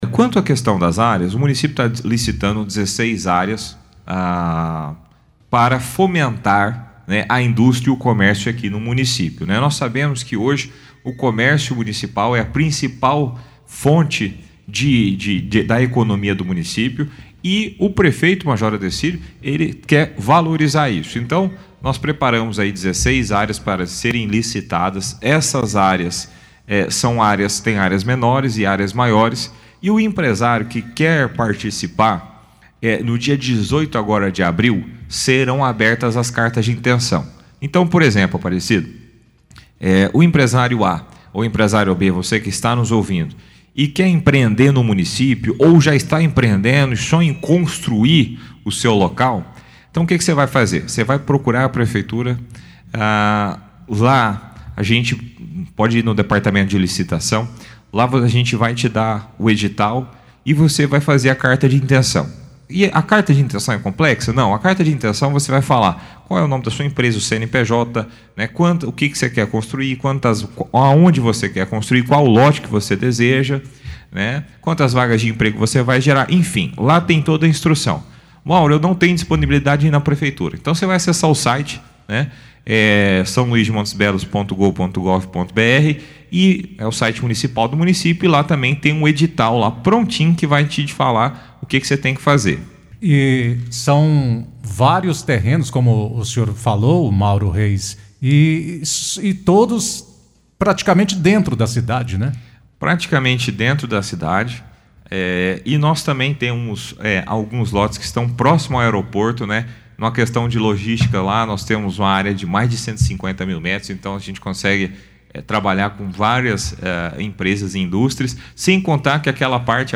Os interessados em concorrer aos terrenos devem procurar a prefeitura e apresentar suas cartas de intenção. No áudio abaixo, o secretário municipal de Planejamento e Desenvolvimento, Mauro Reis, explica como se dará o processo: